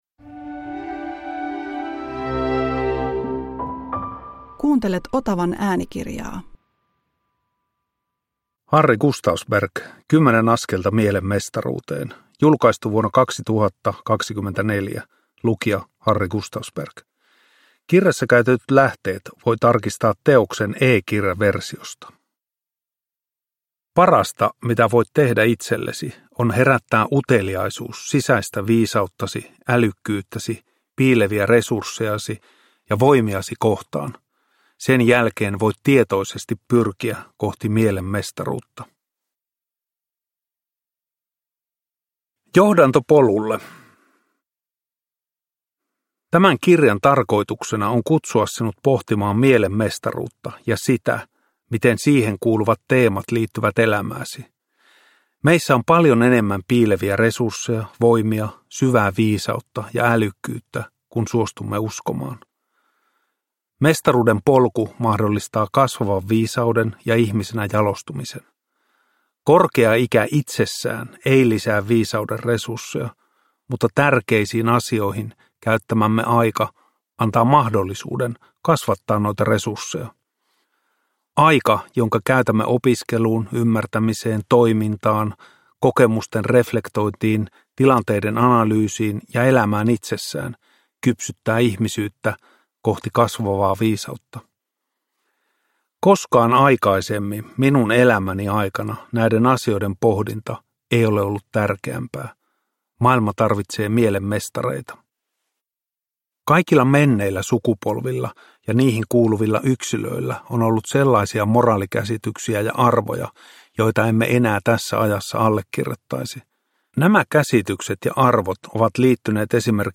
10 askelta mielen mestaruuteen – Ljudbok